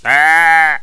khanat-sounds-sources/_stock/sound_library/animals/sheeps/sheep2.wav at main
sheep2.wav